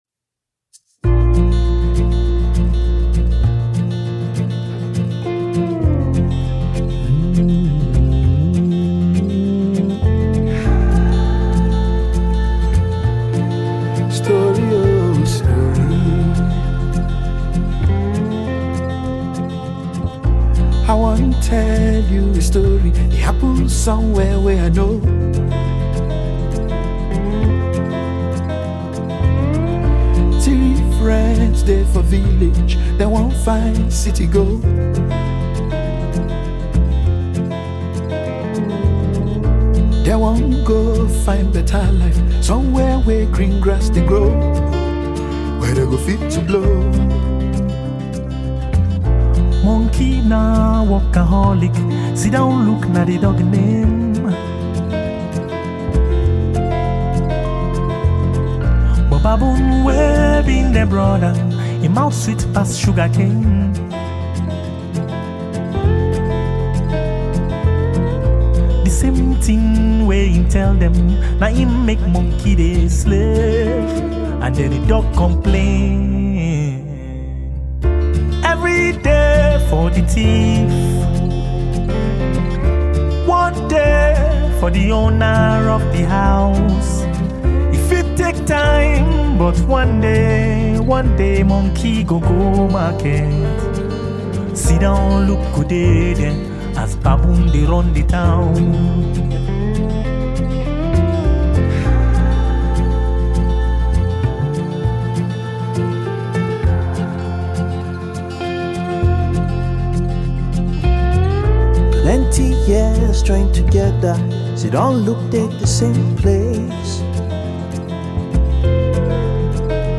Super talented singer/producer
satirical new song